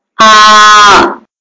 长元音 " aa mono - 声音 - 淘声网 - 免费音效素材资源|视频游戏配乐下载
用在酒吧的元音